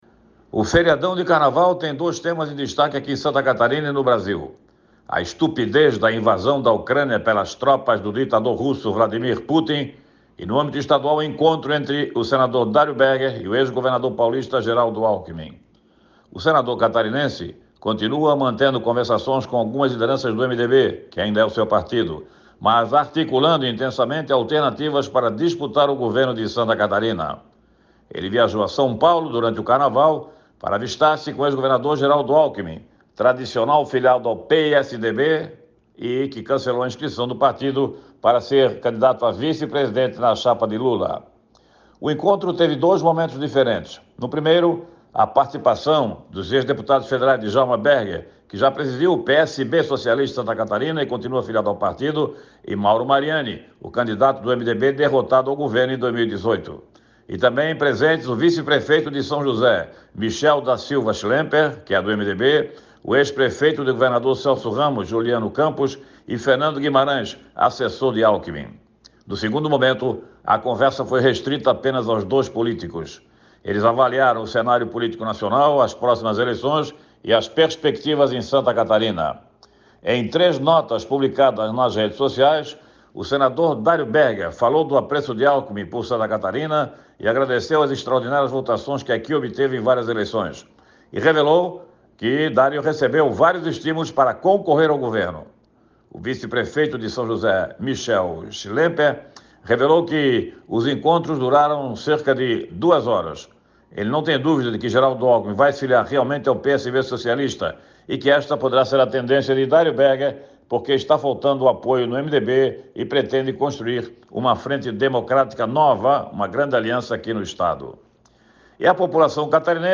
Jornalista destaca a violenta e inaceitável invasão da Ucrânia por tropas russas e ações humanitárias a favor dos ucranianos